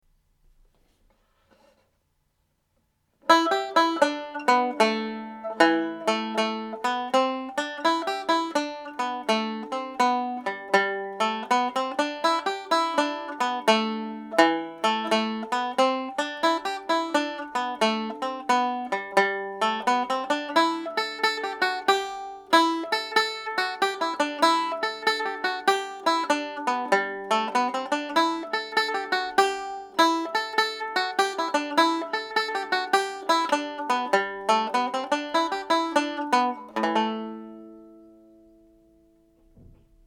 It is a ‘Hop Jig’ although some say it is a slip jig.
Rocky Road to Dublin played slowly
Rocky-Road-to-Dublin_slow.mp3